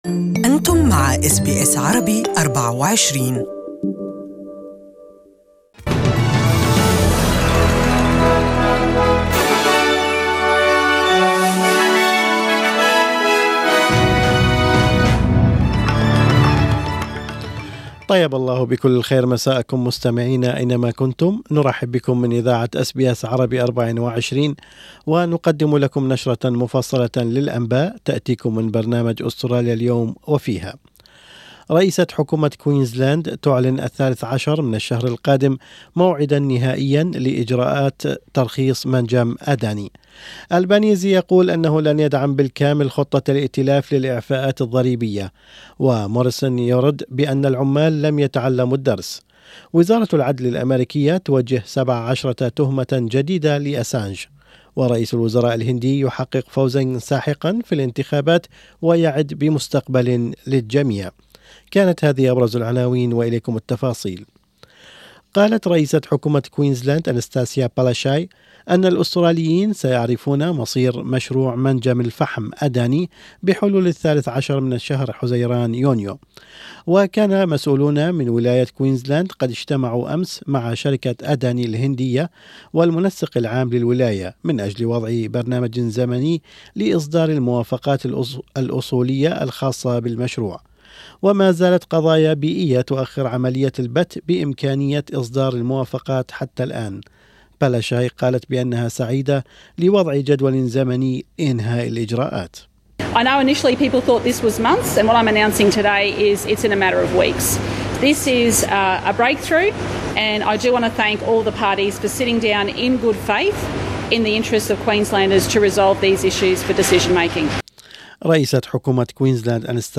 Evening News Bulletin: 1st Energy Power Company fined 20,000 after signing up woman with dementia